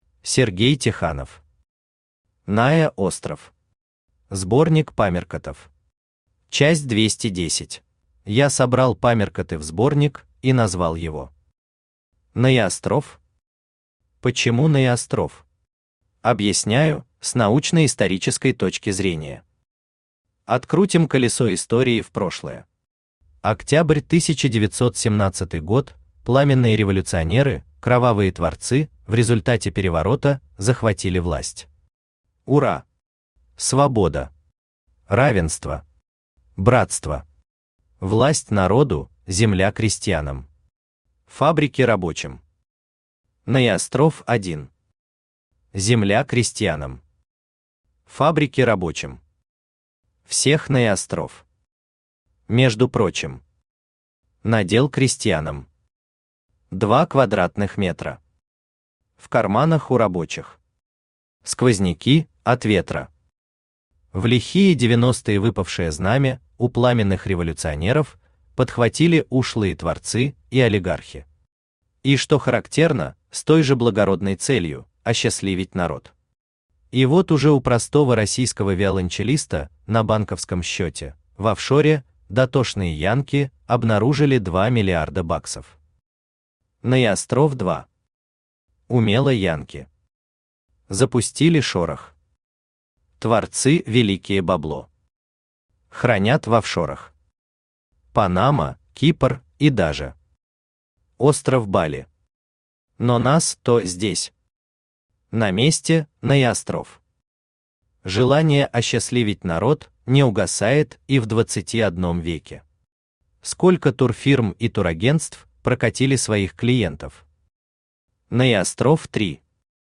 Аудиокнига НаеОстров. Сборник памяркотов. Часть 210 | Библиотека аудиокниг
Читает аудиокнигу Авточтец ЛитРес.